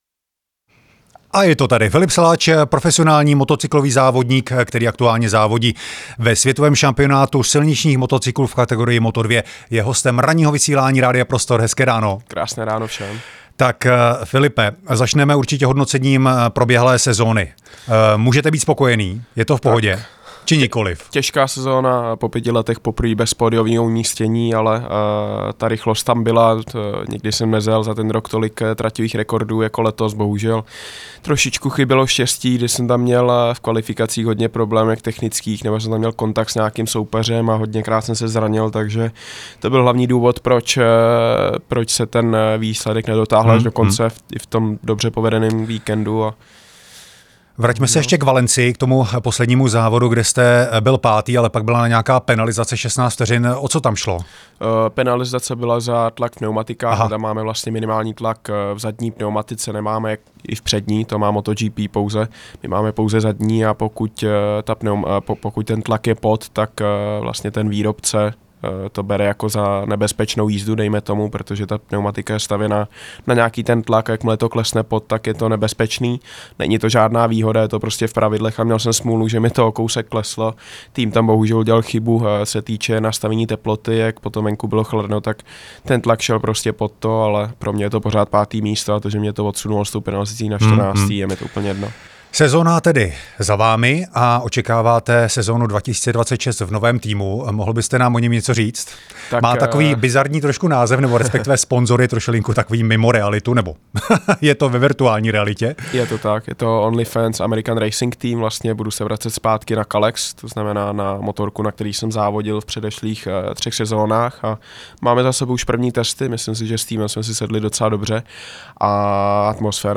V rozhovoru přímo v našem studiu zhodnotil uplynulou sezónu, která přinesla vzestupy i pády. Proč přišel o skvělé umístění ve Valencii? Jak přísné jsou nároky na váhu jezdce a co ho čeká v nejbližší budoucnosti?